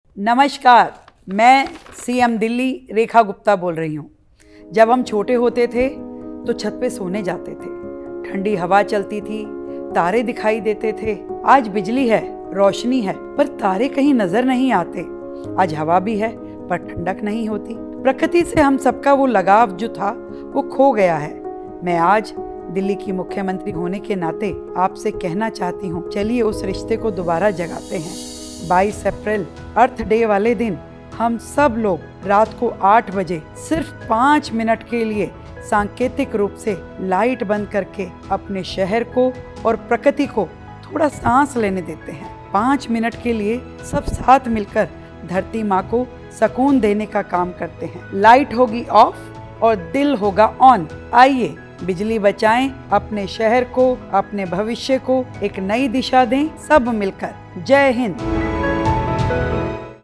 Appeal from Hon'ble Chief Minister, GNCT, Delhi
DELHICMMsg.mp3